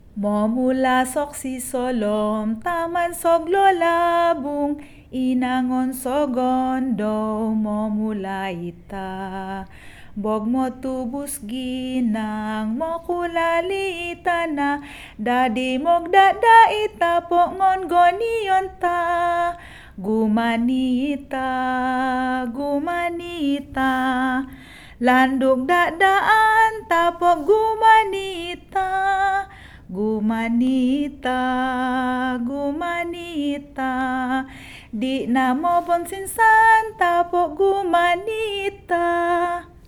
Subanon-Song.mp3